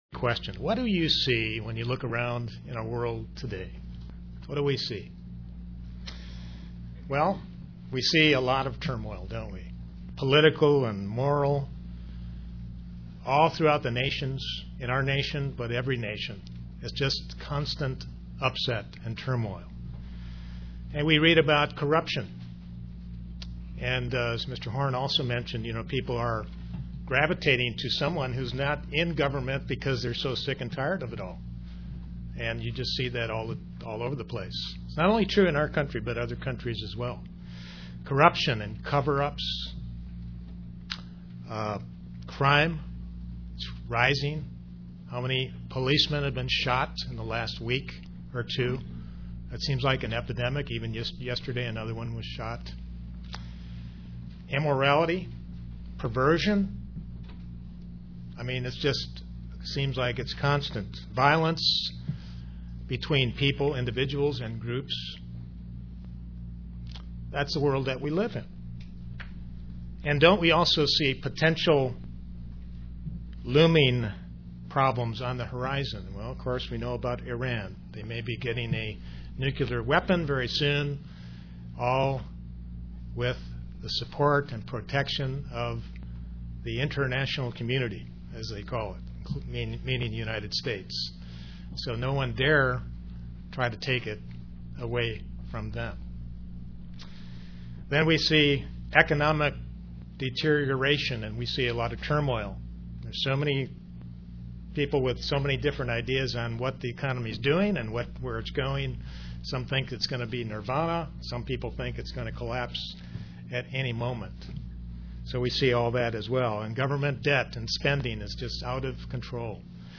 Sermons
Given in Kingsport, TN